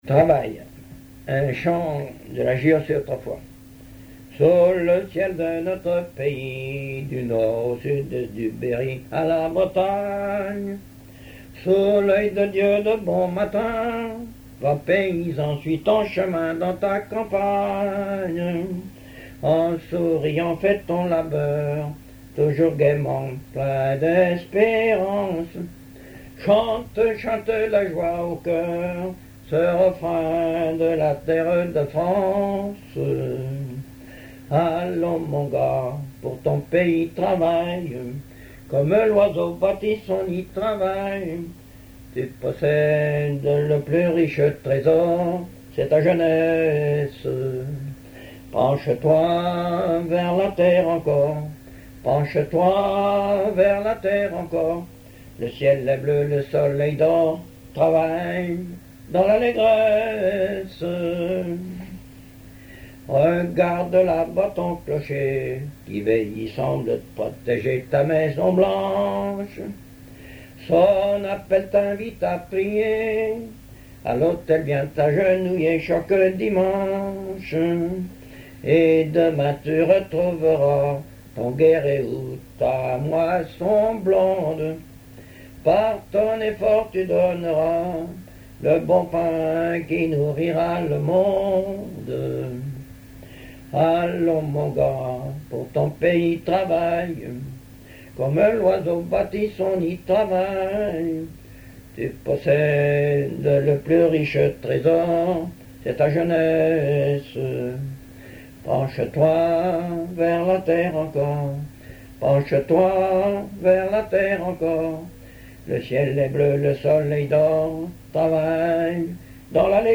Mémoires et Patrimoines vivants - RaddO est une base de données d'archives iconographiques et sonores.
Genre strophique
Catégorie Pièce musicale inédite